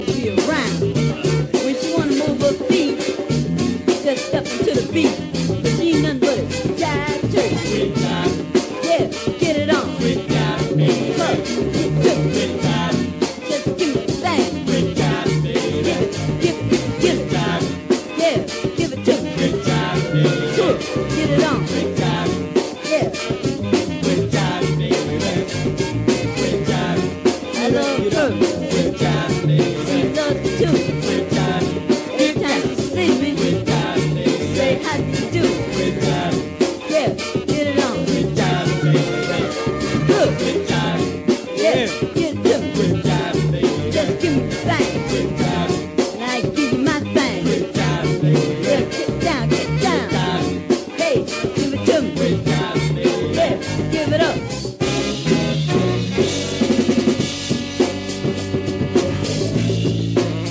raw funk rarity by the Miami-based
this is a double header of mid ‘70s funk fire.